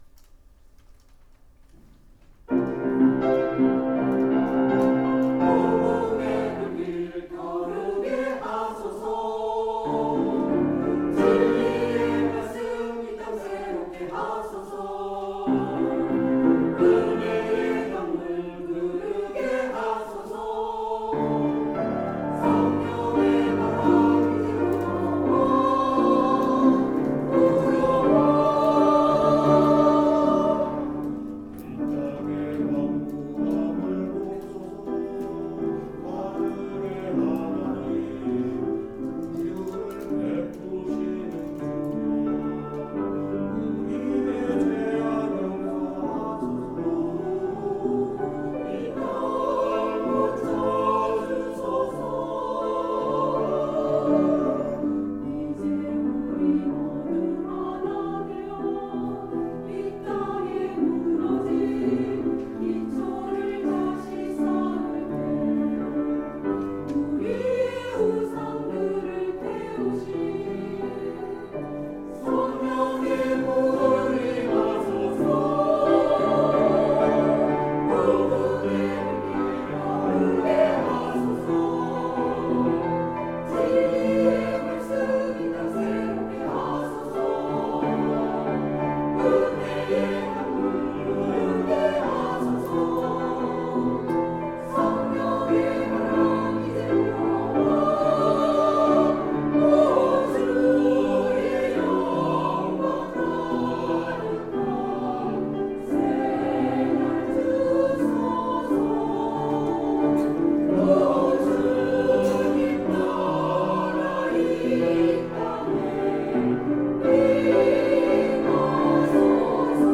찬양대
[주일 찬양] 부흥